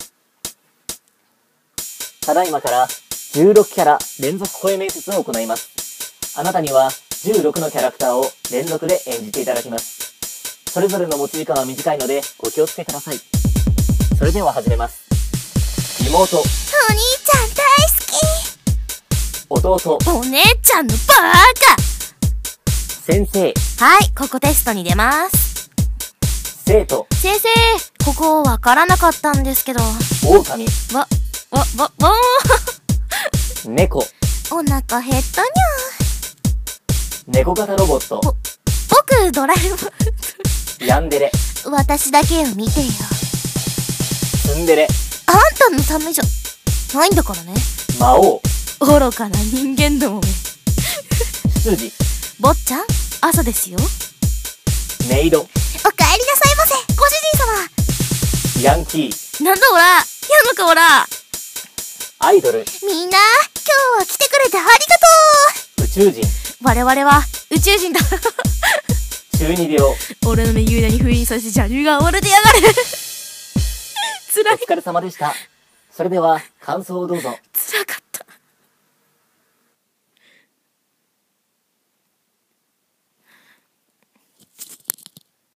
16キャラ連続声面接